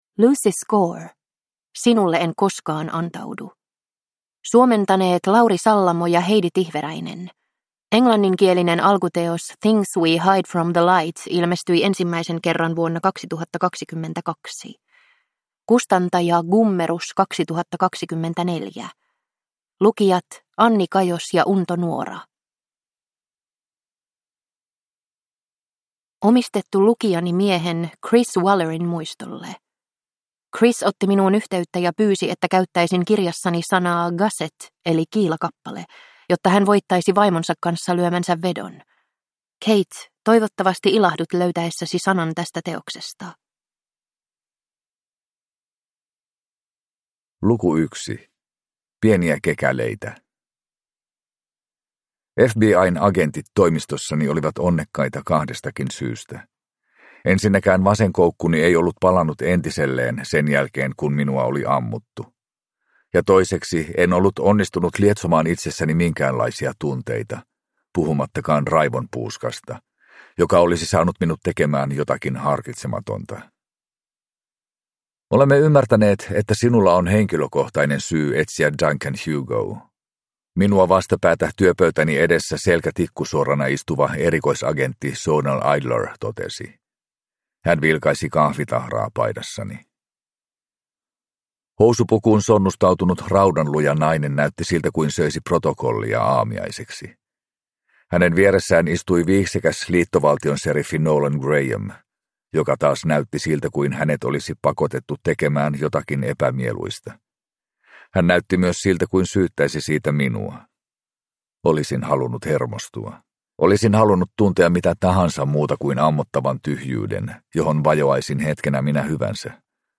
Sinulle en koskaan antaudu (ljudbok) av Lucy Score